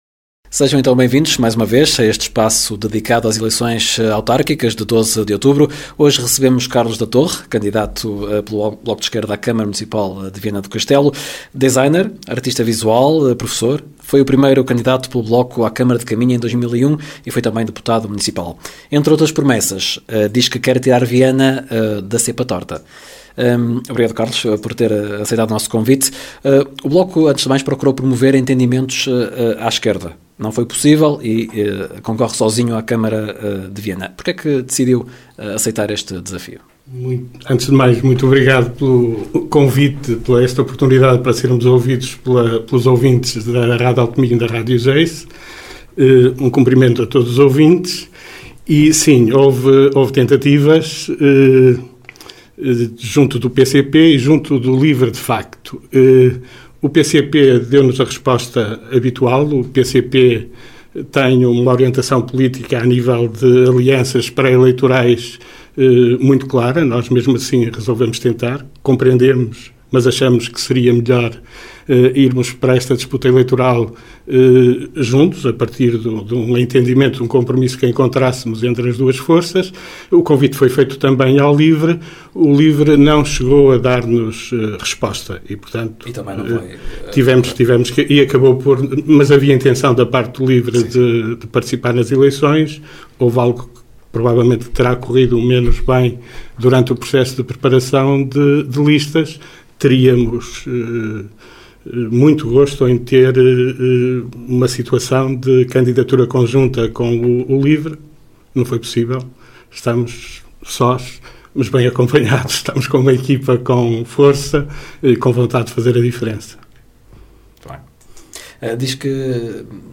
Ação conjunta da Rádio Geice FM e da Rádio Alto Minho, que visa promover um ciclo de entrevistas aos candidatos à presidência da Câmara Municipal de Viana do Castelo.